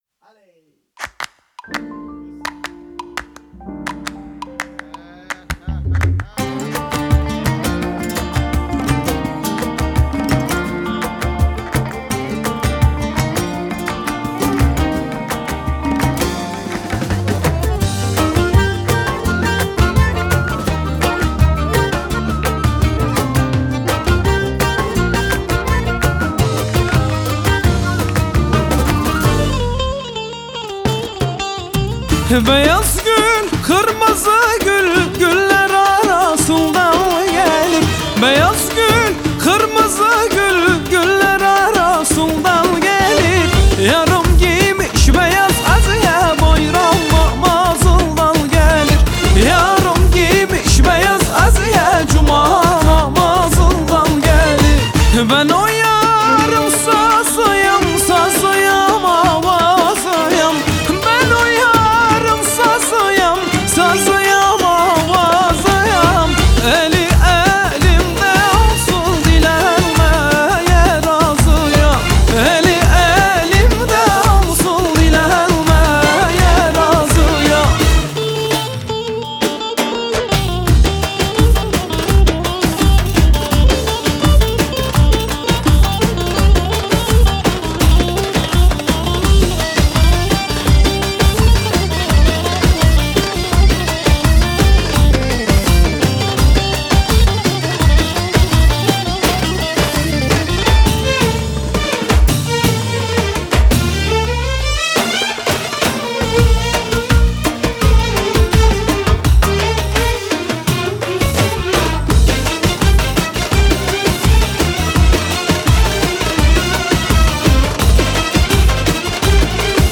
آهنگ ترکیه ای آهنگ شاد ترکیه ای